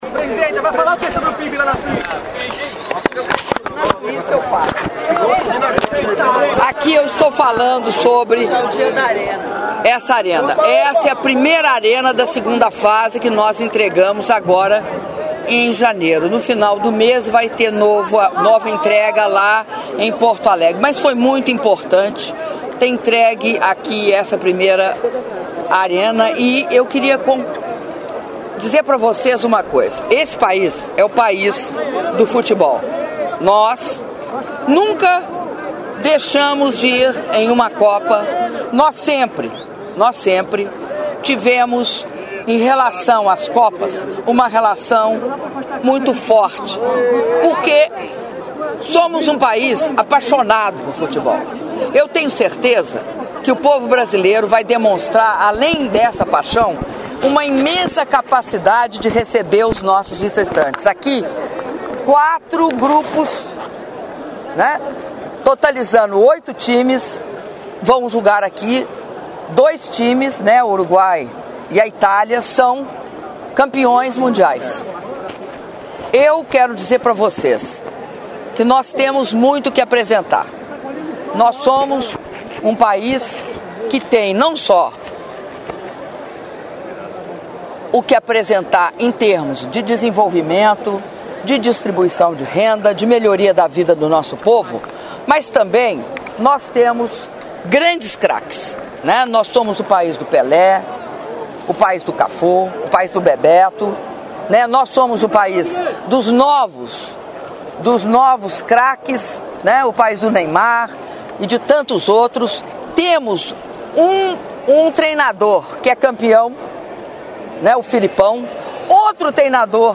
Áudio da entrevista coletiva concedida pela Presidenta da República, Dilma Rousseff, durante visita de inauguração ao Complexo do Estádio Arena das Dunas - Natal/RN (05min16s)